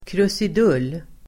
Uttal: [krusid'ul:]